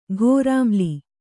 ♪ ghōrāmli